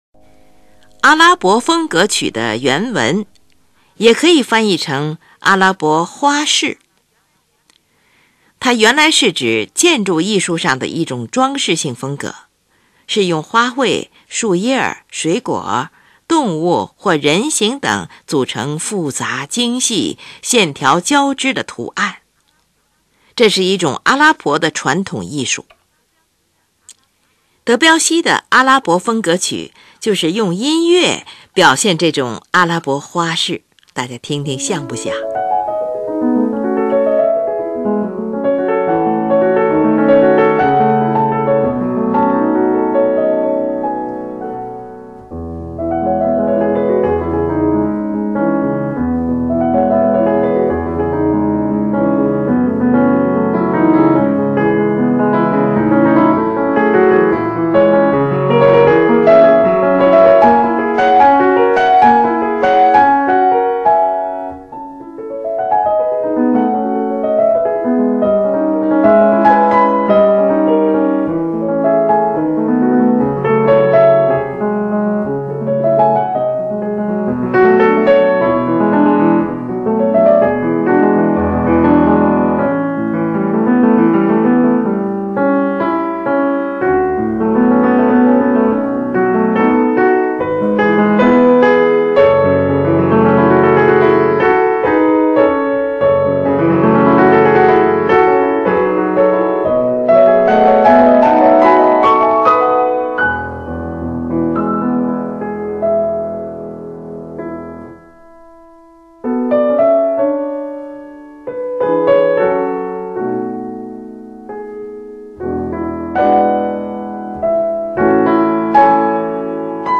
采用单三部曲式
使乐曲散发出来自异国的绚丽神秘色彩